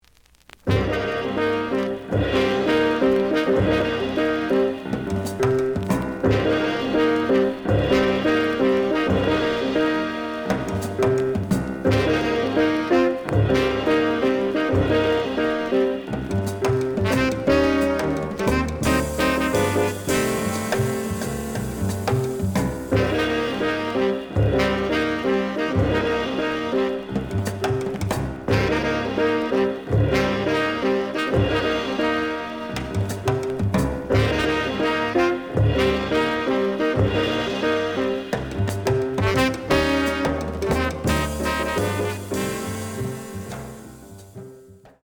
The audio sample is recorded from the actual item.
●Format: 7 inch
●Genre: Hard Bop